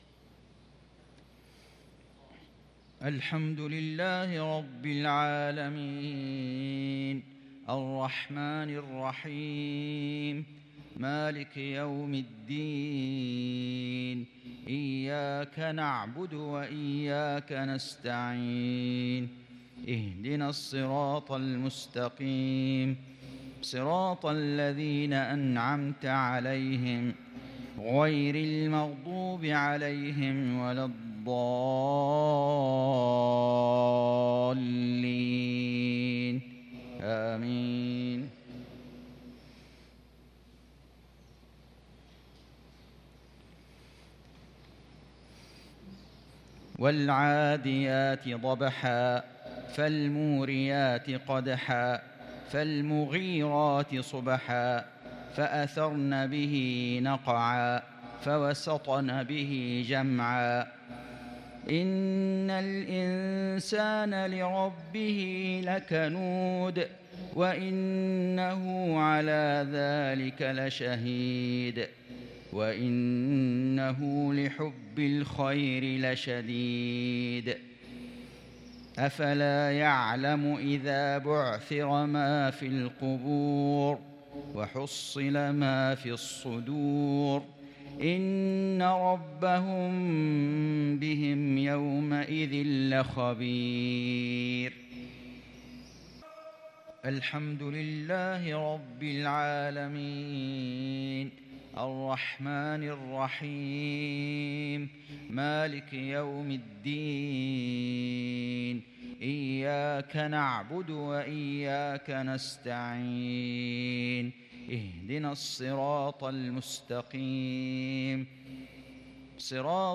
صلاة المغرب للقارئ فيصل غزاوي 7 صفر 1443 هـ
تِلَاوَات الْحَرَمَيْن .